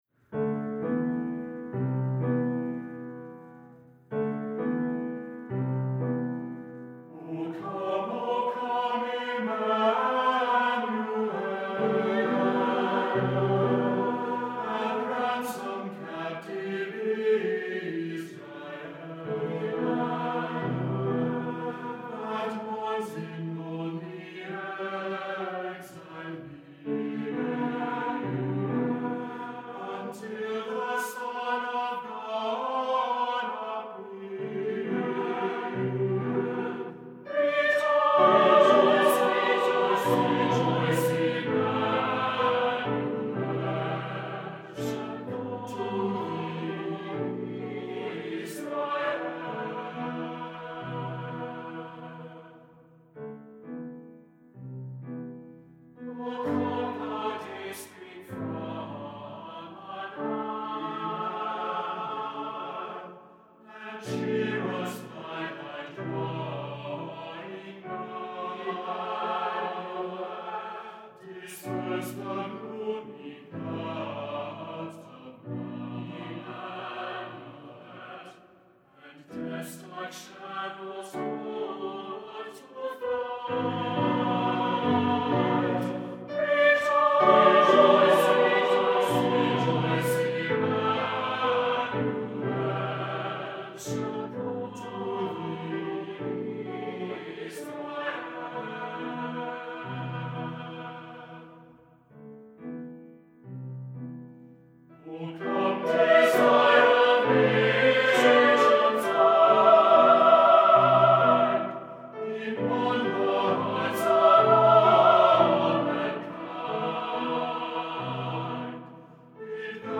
Voicing: Two-Part Mixed Voices and Keyboard